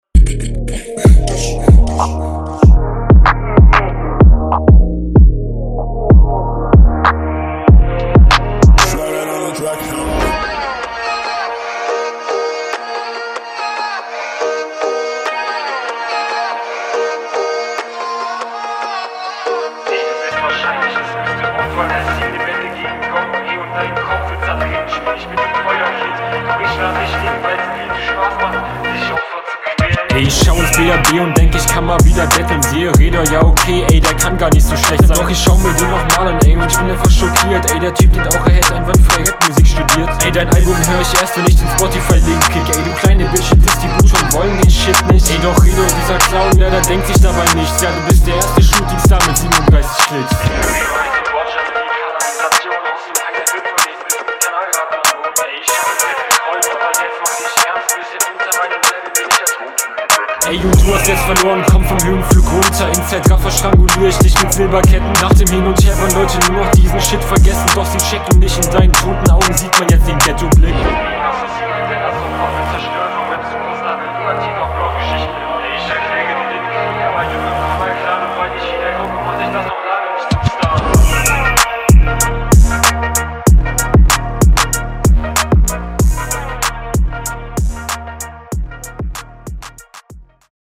stimme bisschen leise leider wenige lines, liegt vielleicht auch an der verständlichkeit